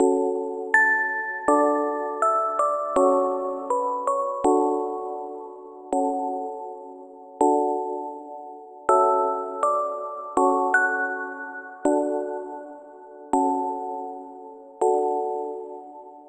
オルゴールのおしゃれな音色のBGMに仕上げました！
BPM：81 キー：D ジャンル：ゆったり 楽器：オルゴール、ストリングス